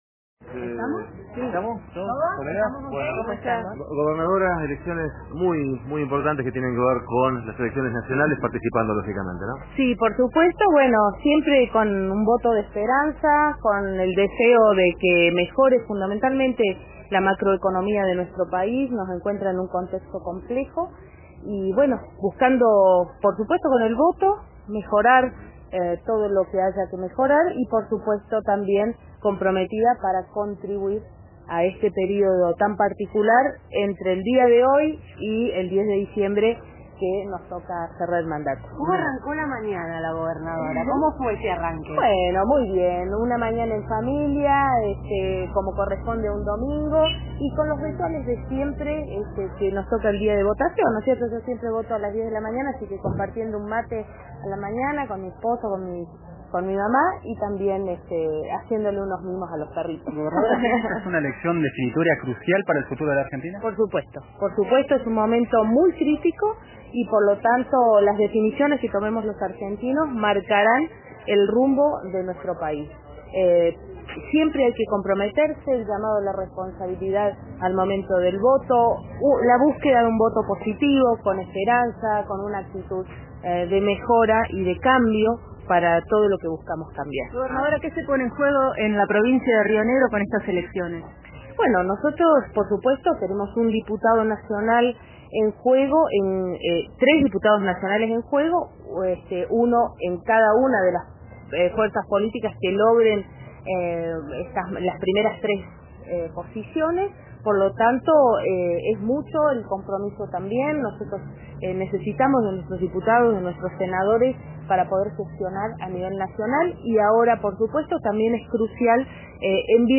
En declaraciones a la prensa y RÍO NEGRO RADIO, compartió las perspectivas electorales en una entrevista realizada momentos antes de emitir su voto en la escuela de Bariloche.
Escuchá a la gobernadora Arabela Carreras, por RÍO NEGRO RADIO